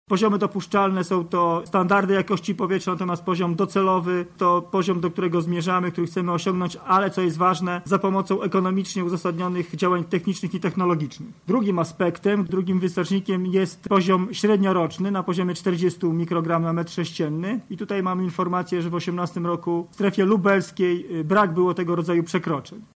O tym, czym są poziomy dopuszczalne mówi Członek Zarządu Województwa Lubelskiego Sebastian Trojak.